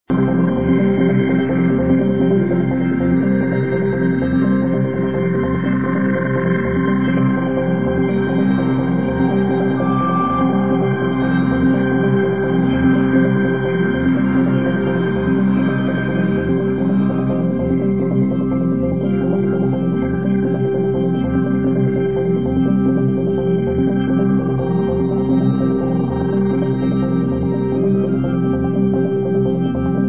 Album studio